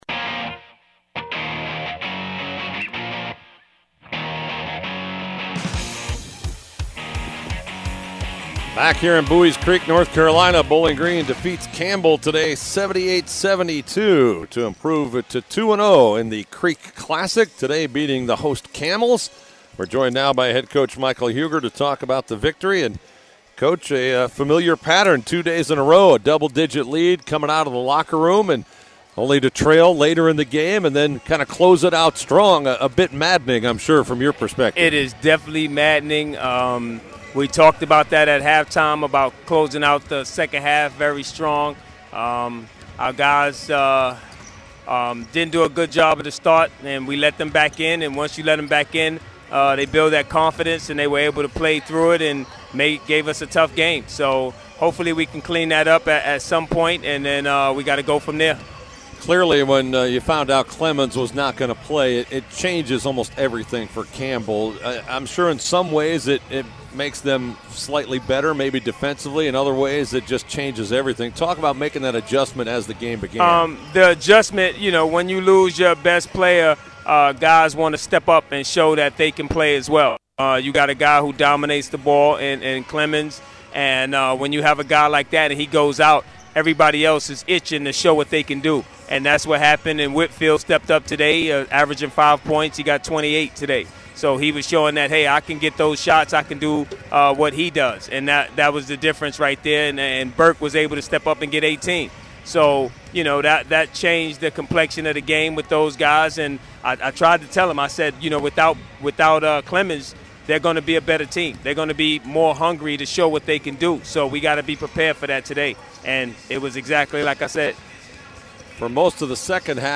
Post-Game Audio: